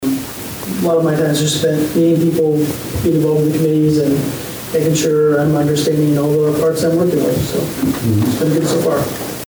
Bockelman has been in Humboldt for about two weeks and he talked at the meeting about his start in the new position.